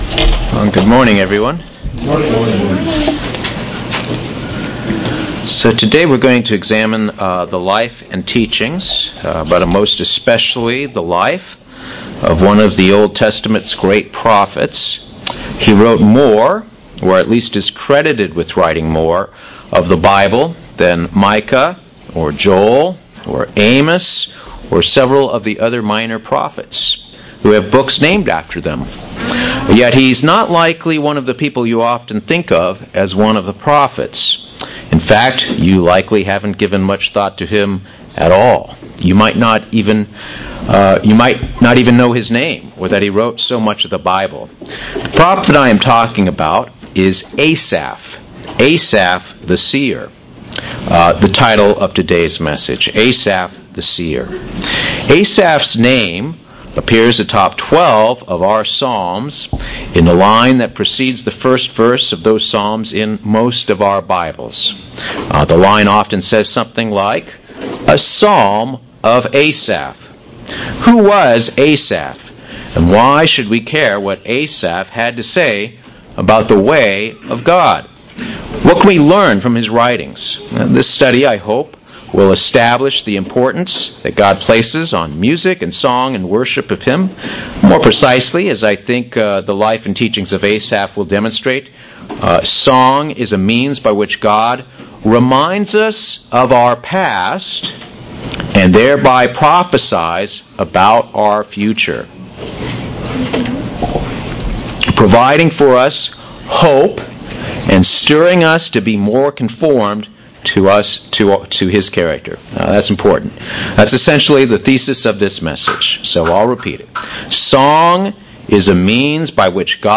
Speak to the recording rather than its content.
Given in Buford, GA